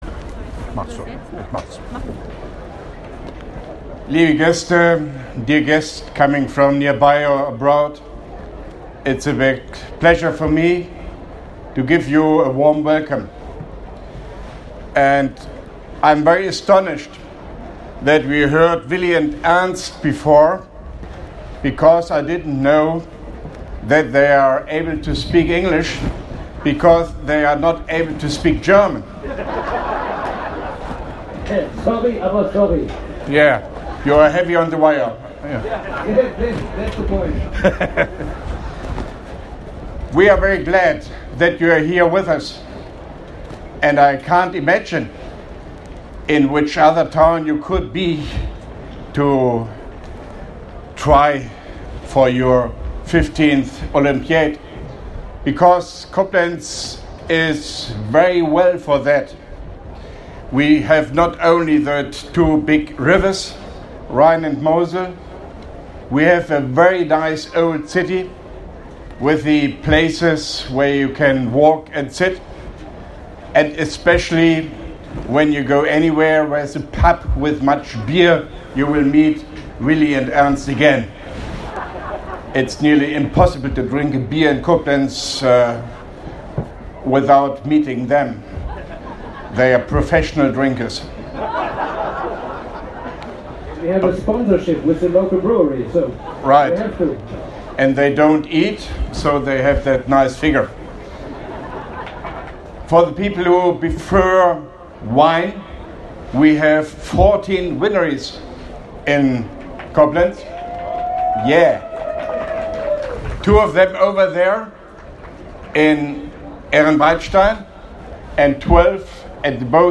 Grußwort (in Englisch) von OB Hofmann-Göttig bei der Eröffnung der 15. Internationalen Volkssport-Olympiade des Volkssportvereins “Schnelle Füße”, Koblenz 06.06.2017